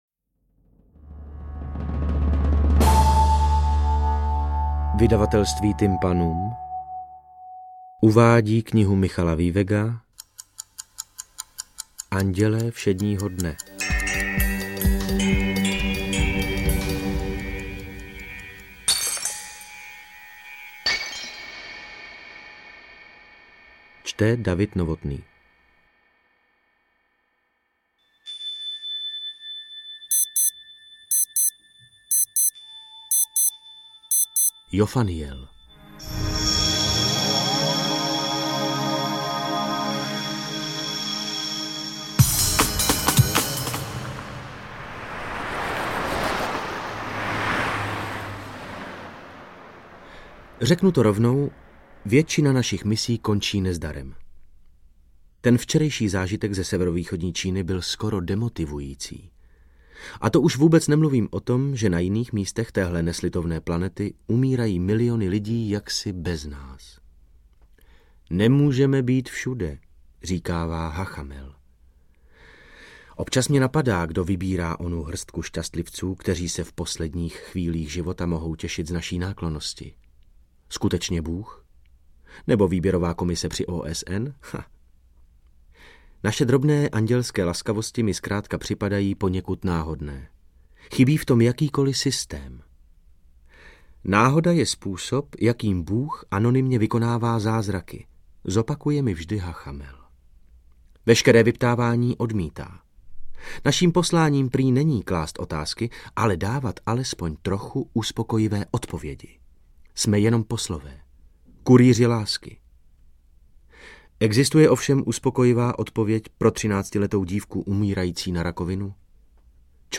Audiokniha ve formátu MP3.. Můžete se poslechnout plnou verzi bestselleru oblíbeného autora v podání Davida Novotného.